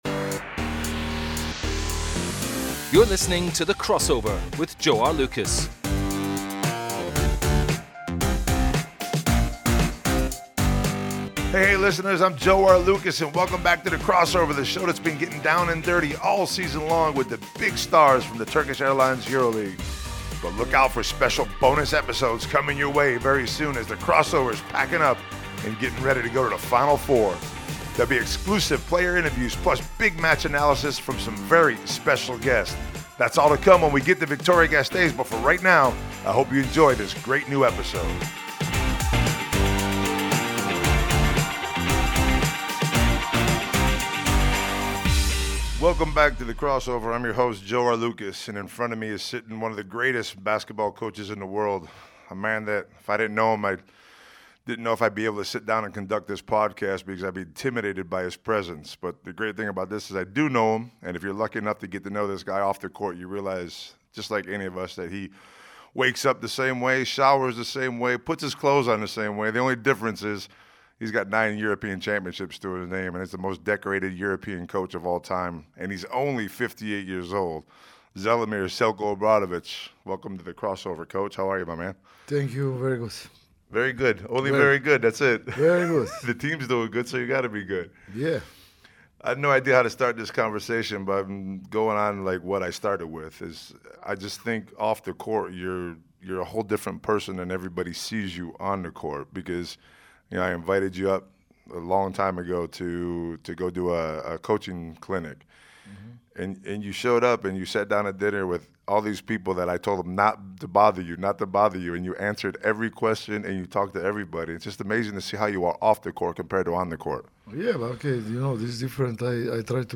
Joe Arlauckas sits down with his old friend and legendary former coach Zelimir "Zeljko" Obradovic.